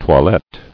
[toi·lette]